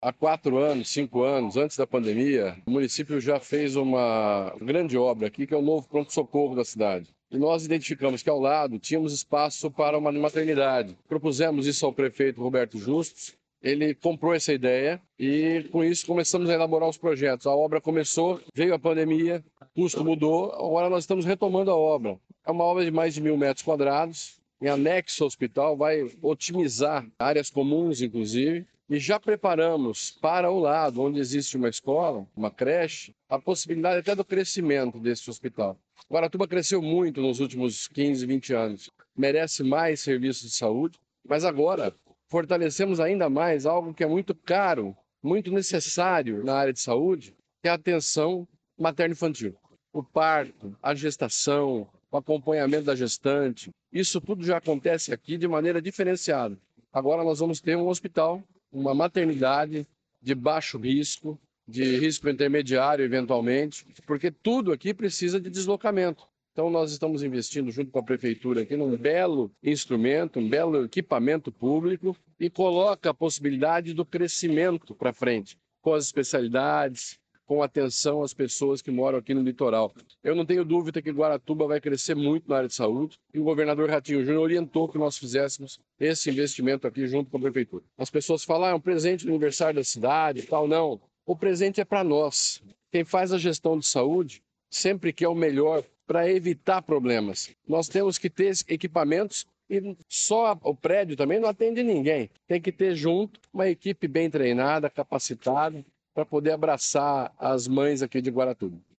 Sonora do secretário da Saúde, Beto Preto, sobre a nova maternidade e pavimentação em Guaratuba